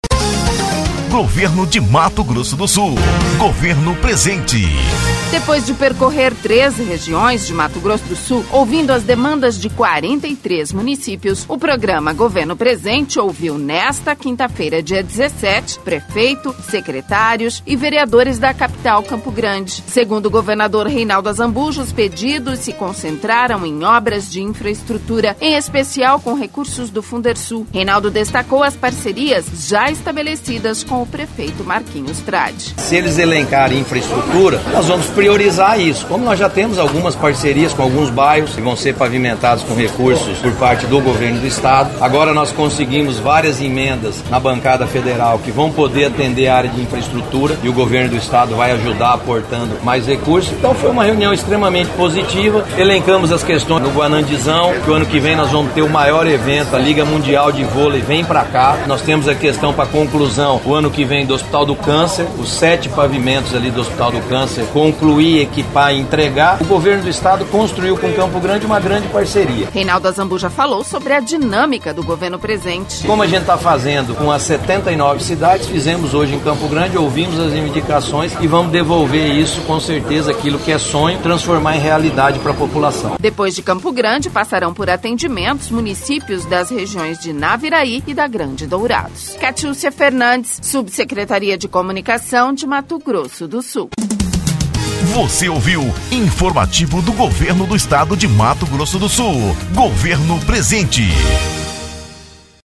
Reinaldo Azambuja falou sobre a dinâmica do Governo Presente.